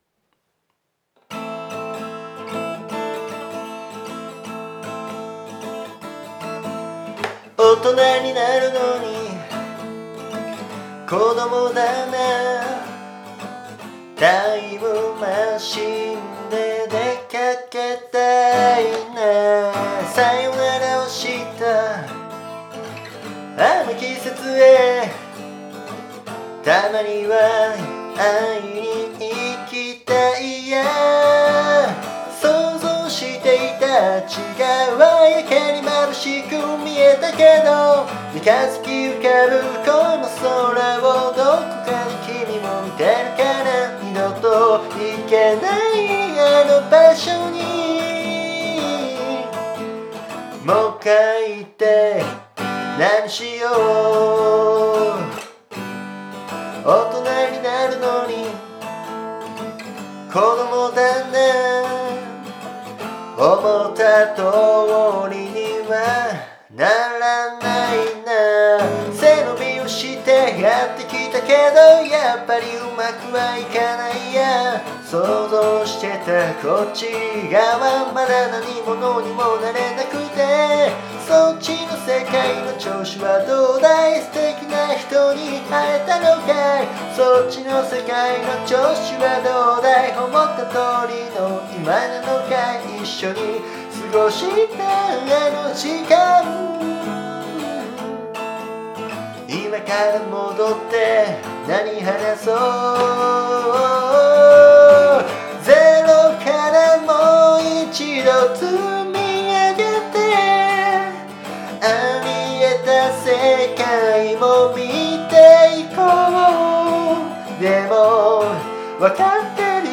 家でボリュームを気にしつつ歌ってみたよ。ただ花粉症なのか鼻がグズグズで声も少しかすれちゃったんだけど、一旦勢いで歌ってみたよ。
ちなみに僕が曲を作る時、基本的にはメジャーコード（明るい）かマイナーコード（暗い）で、どっちにしようかなーなんて考えるんだけど、今回の詩を眺めながら思ったのはちょうど中間かなってことだね（いや、どういうことやねん）。